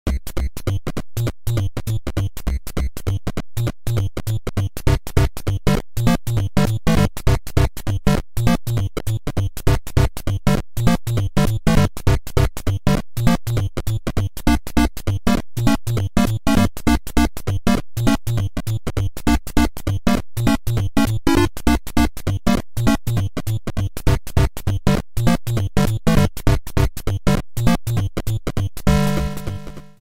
added fadeout